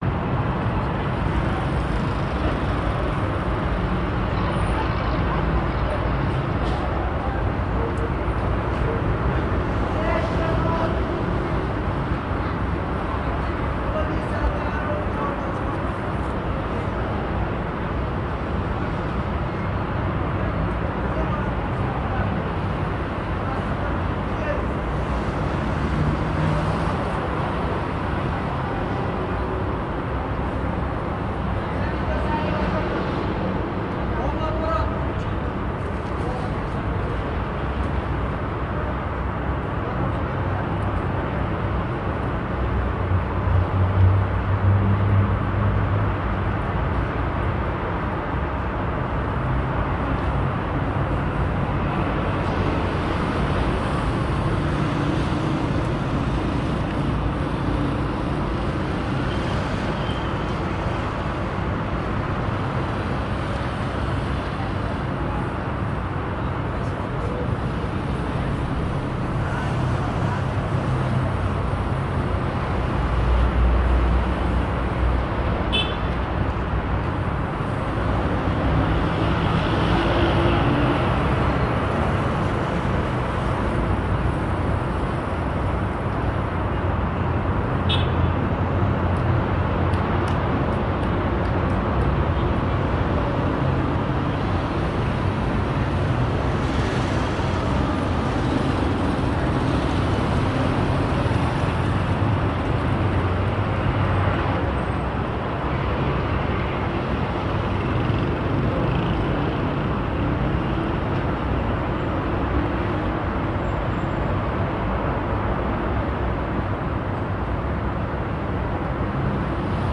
城市公路的鸟儿鸣叫
描述：城市的鸟儿鸣叫，高速公路的持续音，几辆汽车通过，以及其他城市环境声音。
标签： 城市声音 氛围 环境 实地录音 高速公路 鸟类 MIC 汽车 远地点 气氛
声道立体声